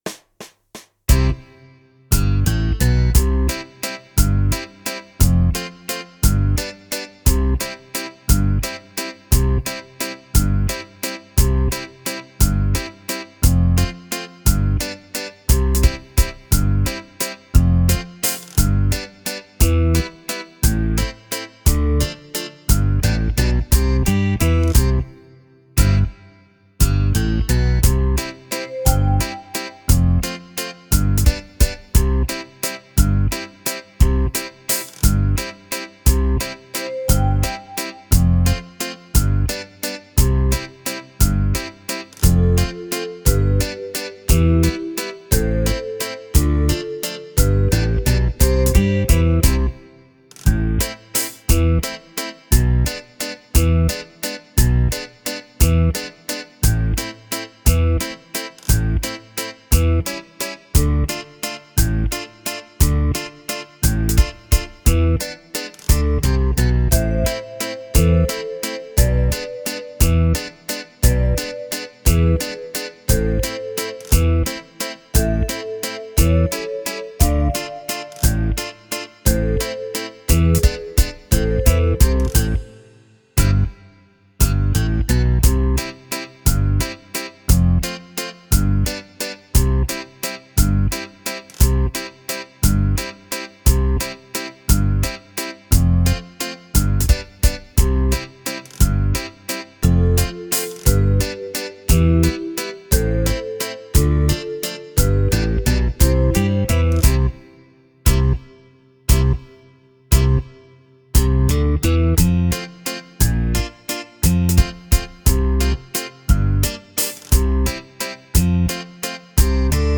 Scarica la base con i cori di questo eccezionale brano